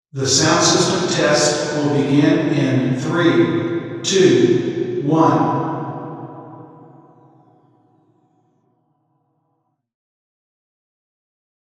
Figure 5 - This room exhibits significant modal and reverberant behavior.
The RIR of an omnidirectional sound source was gathered at 3 test positions using an omnidirectional microphone (Figure 6). Note that the distances are log-spaced.
↑ Speech at TP2